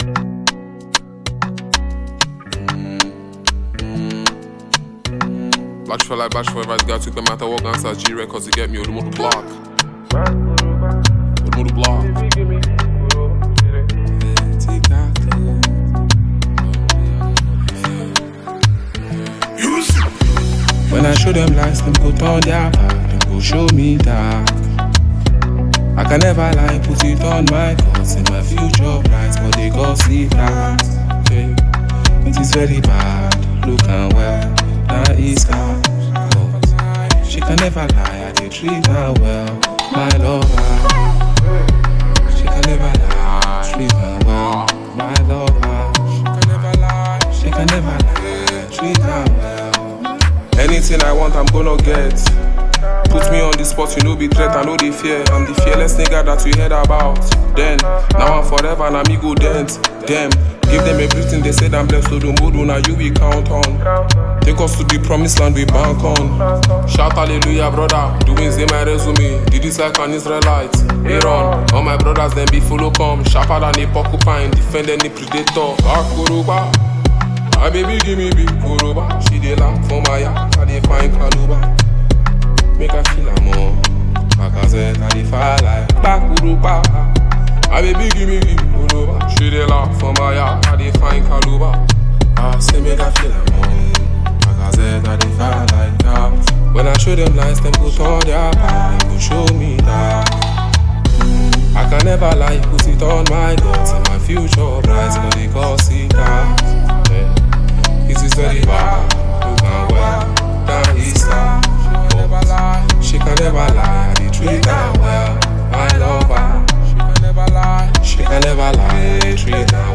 Nigerian rapper and music star
With its catchy beat and well-written lyrics